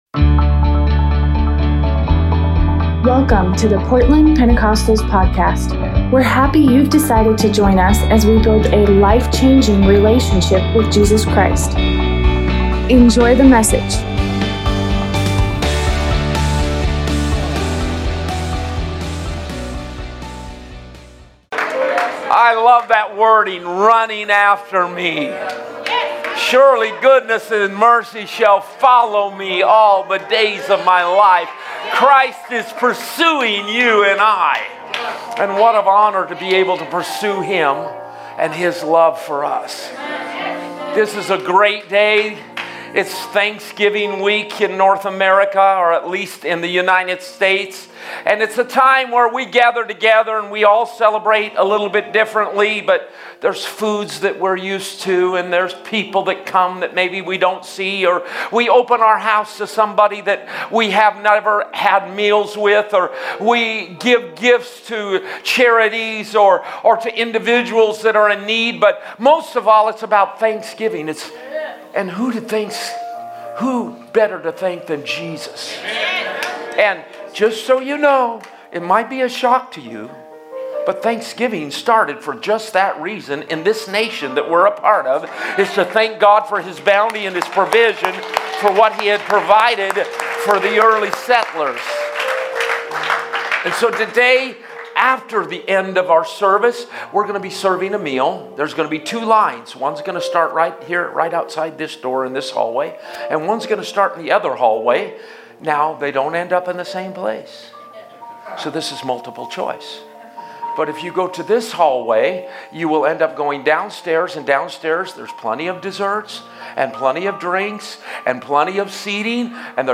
Thanksgiving sermon